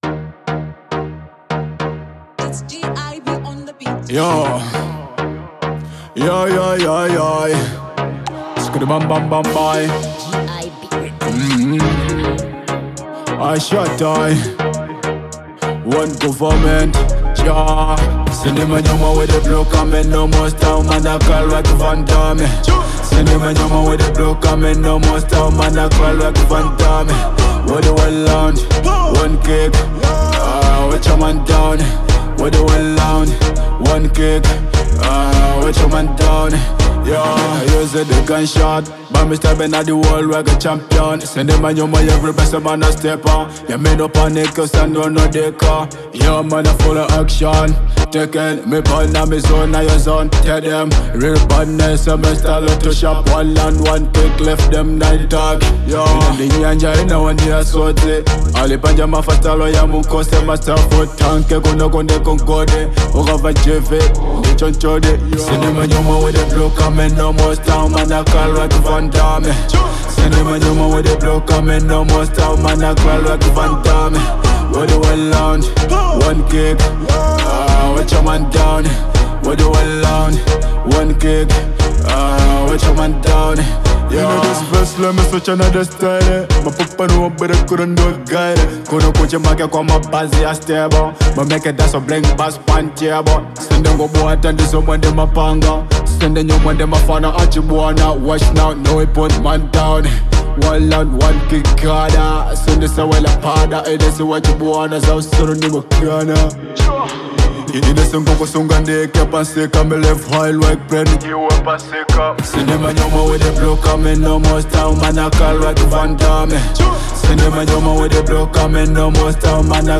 Dancehall • 2025-07-11